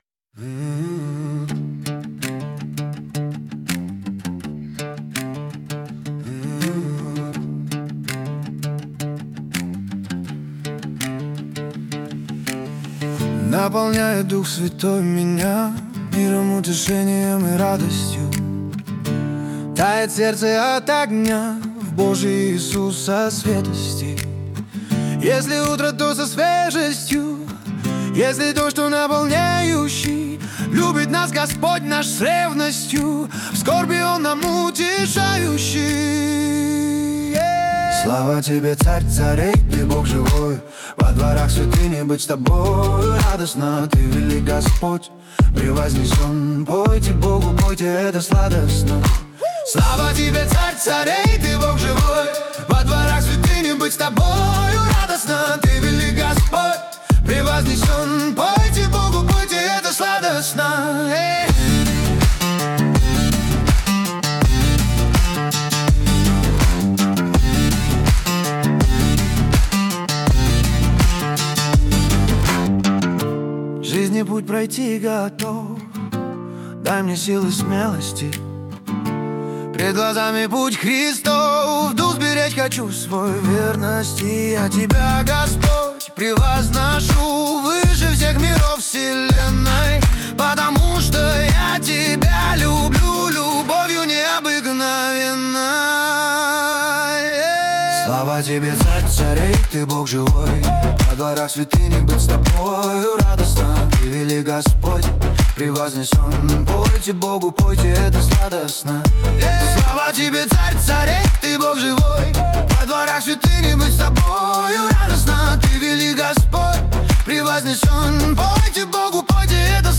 песня ai
26 просмотров 80 прослушиваний 6 скачиваний BPM: 82